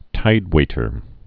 (tīdwātər)